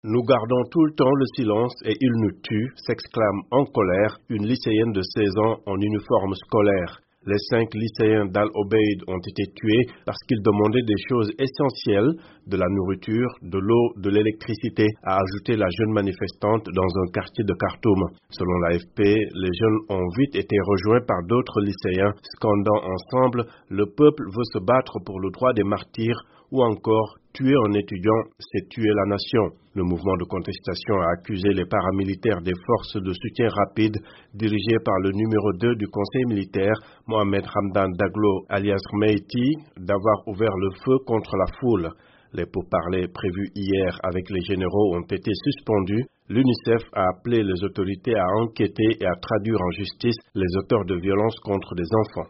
Après la mort de 5 lycéens, les jeunes Soudanais en colère défilent à Khartoum